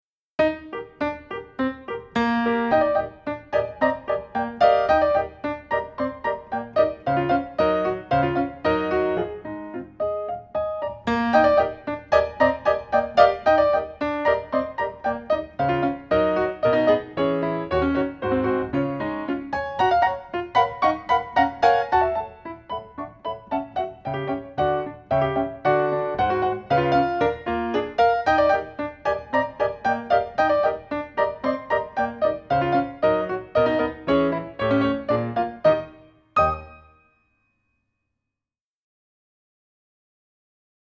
48 piano pieces for classic dance free class
pianoforte